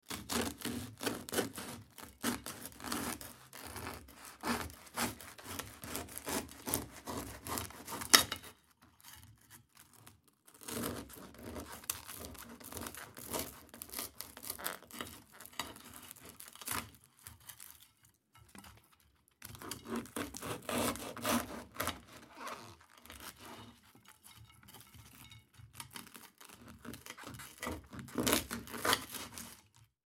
Звуки хлеба
Нарезка свежего батона ножом с зубчиками